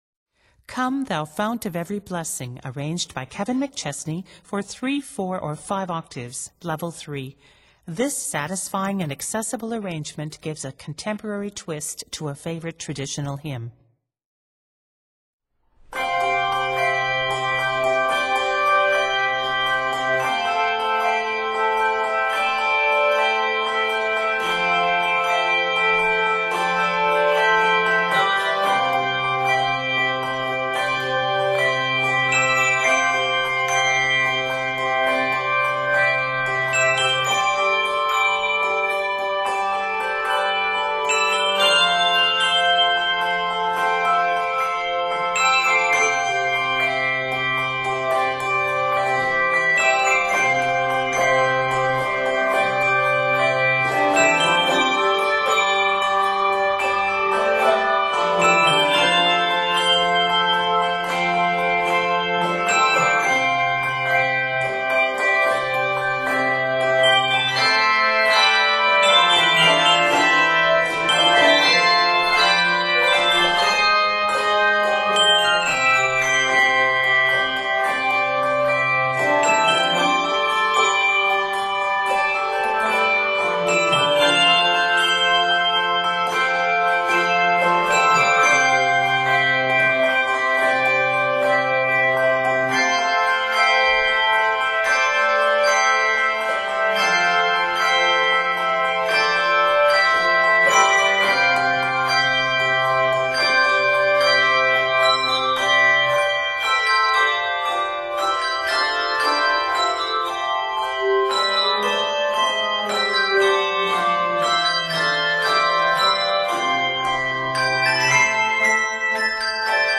traditional hymn
Using syncopation in the melody line
is scored in C Major.